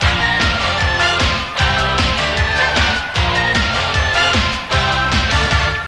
Aerobic Riff
aerobic_jingle.mp3